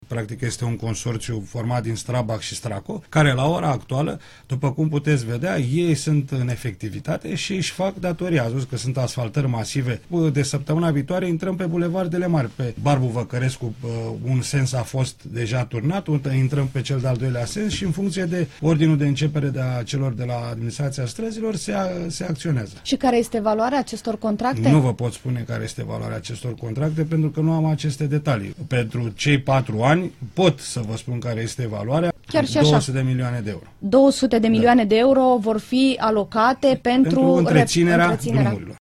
La Interviurile Europa FM, viceprimarul Aurelian Bădulescu a mai spus că în acest an, RATB va fi transformată în societate comercială, fară a se recurge însă la disponibilizări.